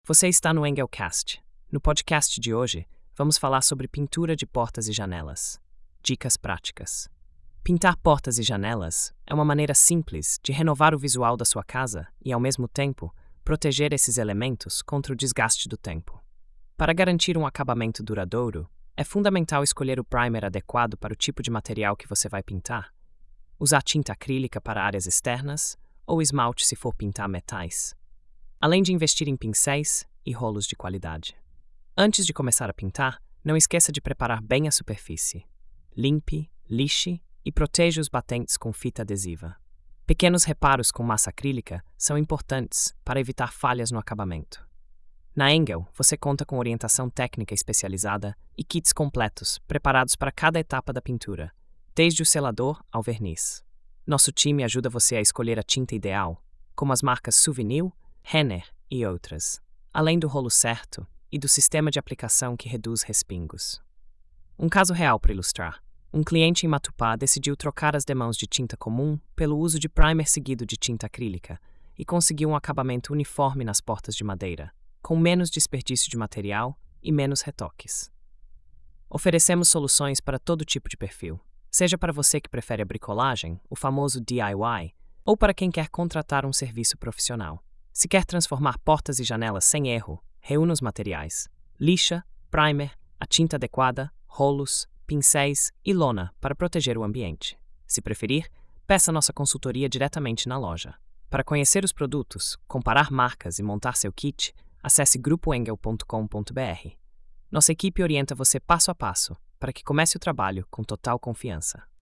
Narração automática por IA • Construção & Reformas